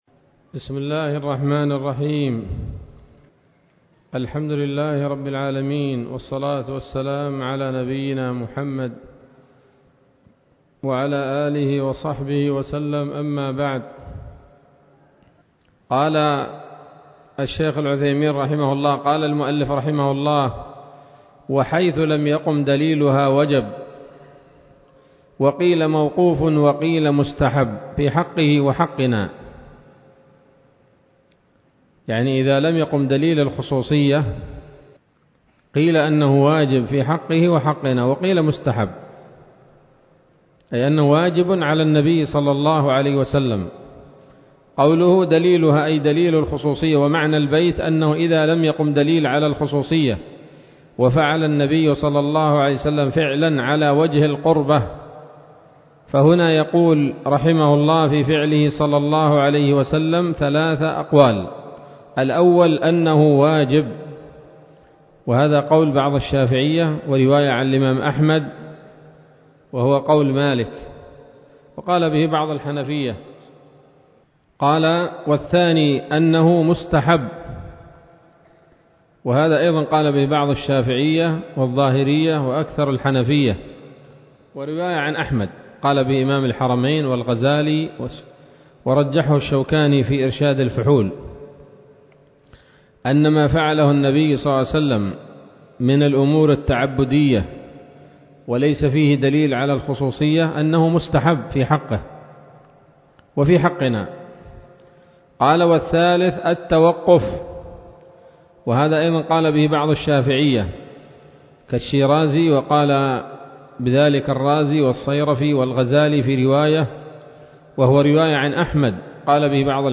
الدرس السابع والأربعون من شرح نظم الورقات للعلامة العثيمين رحمه الله تعالى